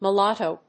音節mu・lat・to 発音記号・読み方
/m(j)ʊlˈæṭoʊ(米国英語), mʌˈlɑ:təʊ(英国英語)/